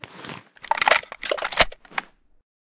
sa58_reload_new.ogg